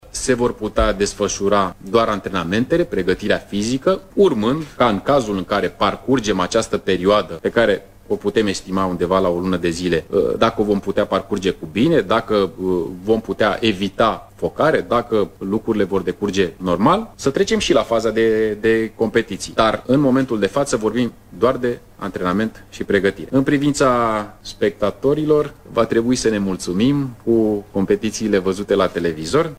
Setul de condiţii a fost prezentat, astăzi, într-o conferinţă de presă, de ministrul Tineretului şi Sportului, Ionuţ Stroe. Stroe a precizat că nu se poate vorbi despre competiții decât cel devreme peste o lună: